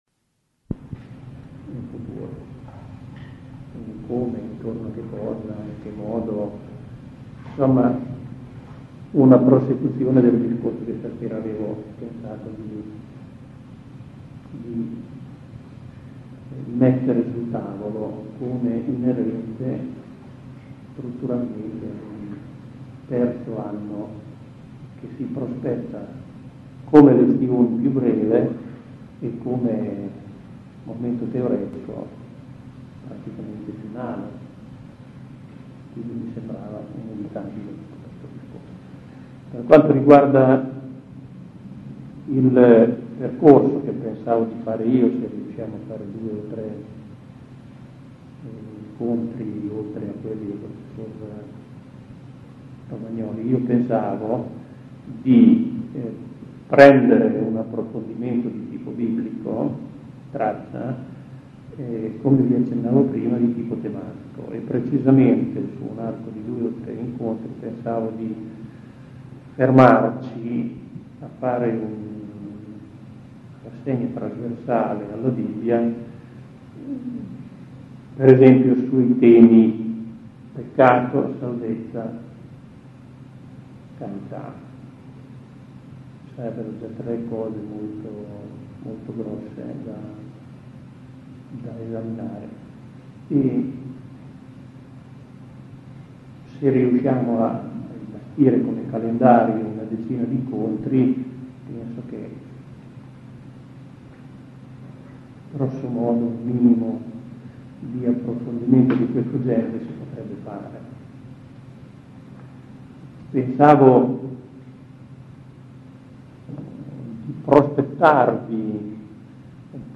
Contributi audio - registrazioni delle lezioni